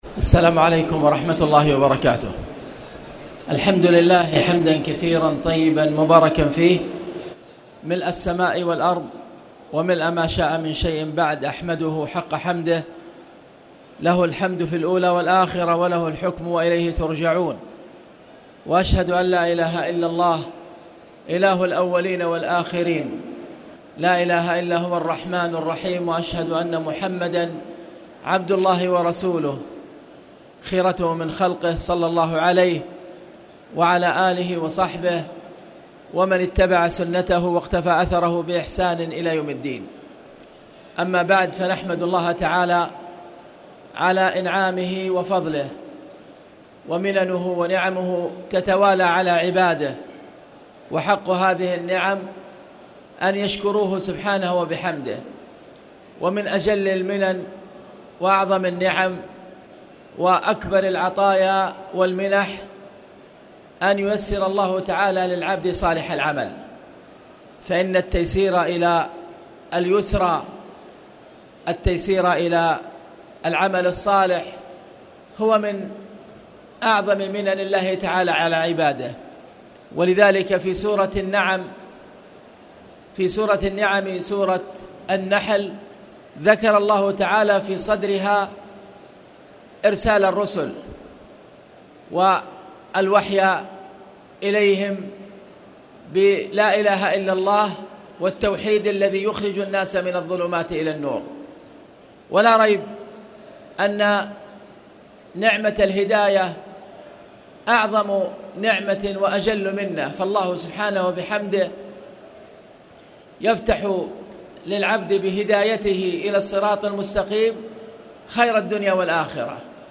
تاريخ النشر ٢٠ رمضان ١٤٣٨ هـ المكان: المسجد الحرام الشيخ